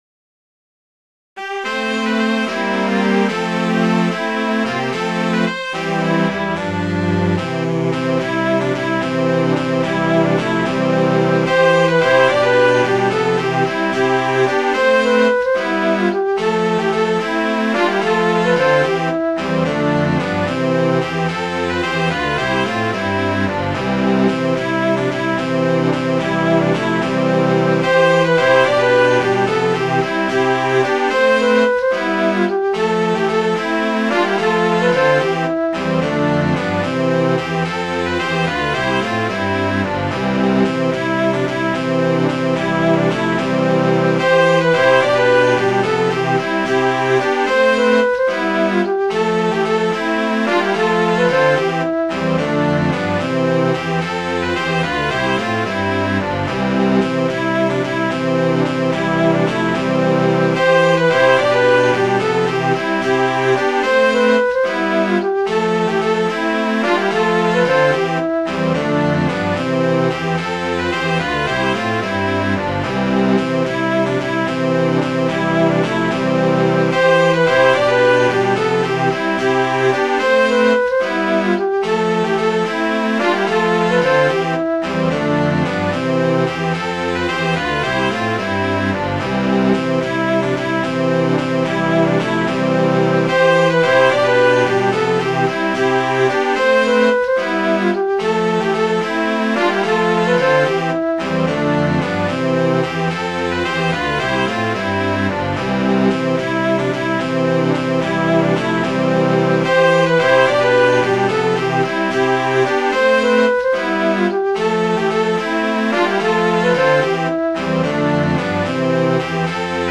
Midi File, Lyrics and Information to Kellyburnbraes
kellybrn.mid.ogg